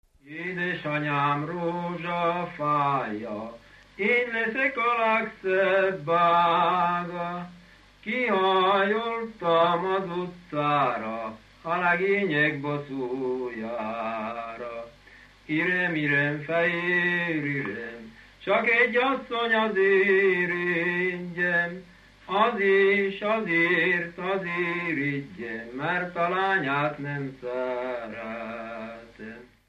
Dunántúl - Vas vm. - Alsóőr
ének
Stílus: 9. Emelkedő nagyambitusú dallamok
Szótagszám: 8.8.8.8
Kadencia: 1 (5) 1 1